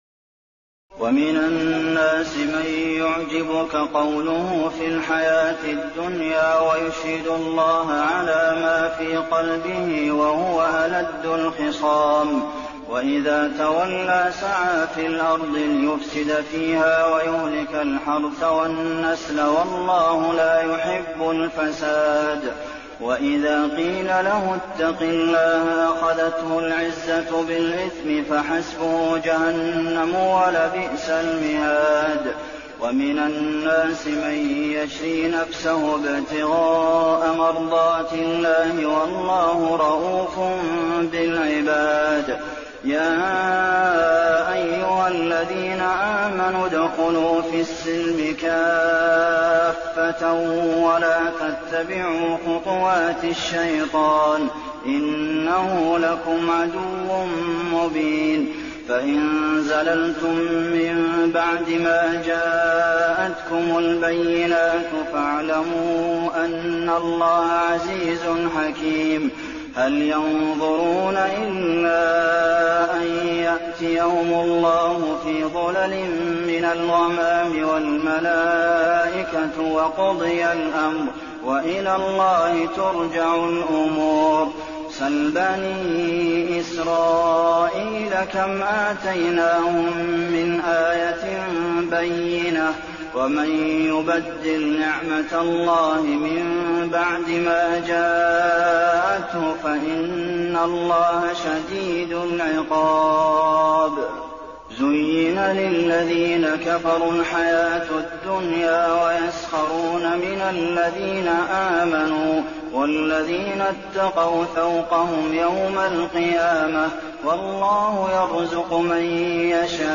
تراويح الليلة الثانية رمضان 1422هـ من سورة البقرة (204-252) Taraweeh 2 st night Ramadan 1422H from Surah Al-Baqara > تراويح الحرم النبوي عام 1422 🕌 > التراويح - تلاوات الحرمين